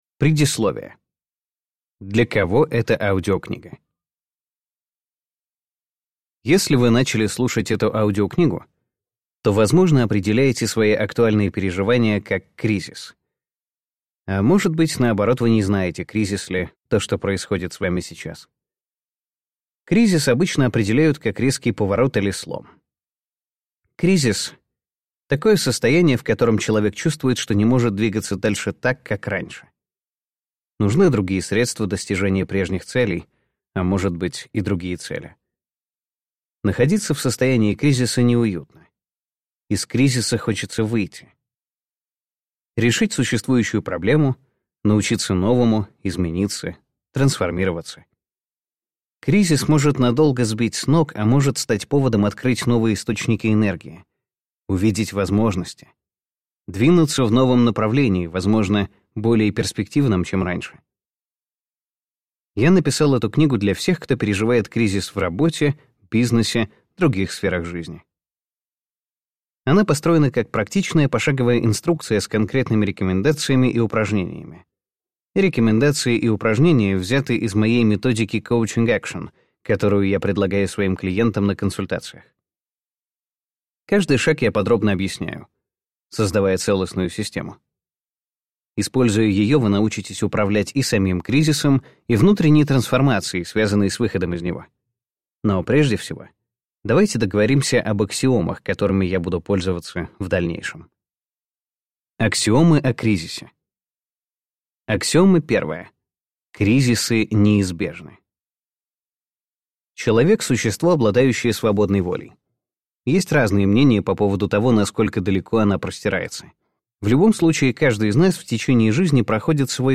Аудиокнига Сила кризиса. Личностная трансформация и новые возможности в трудные времена | Библиотека аудиокниг